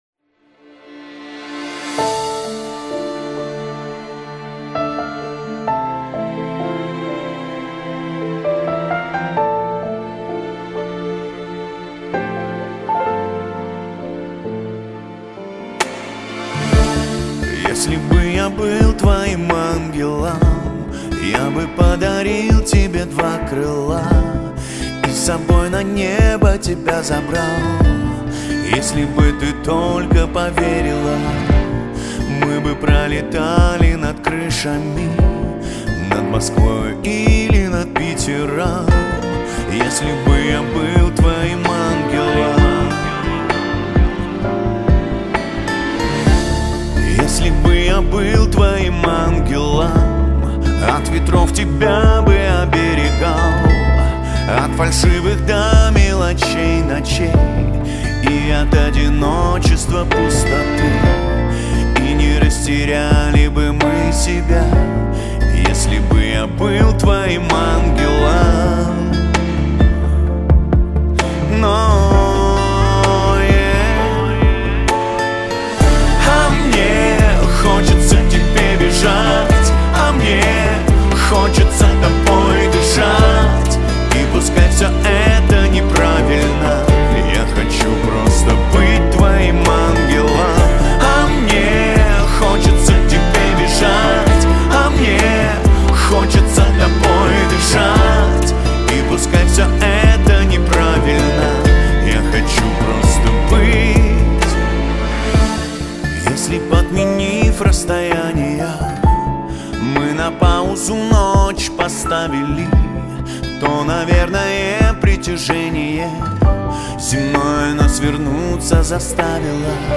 Русские поп песни